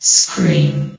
CitadelStationBot df15bbe0f0 [MIRROR] New & Fixed AI VOX Sound Files ( #6003 ) ...
scream.ogg